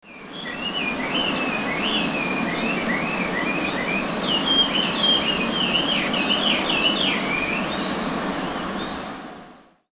シジュウカラやホトトギスのようにリズムが決まった短いさえずりなら耳が慣れるが，その鳥のさえずりはかなり長く，勝手気ままな感じなので，どうにも騒がしい。
騒がしい鳥の声
他の鳥の鳴き真似がうまいヒヨドリかもしれない。
2022年追記:　どうもこの鳥は「ガビチョウ」じゃないかと思うようになりました。
yakamashiiyoo.mp3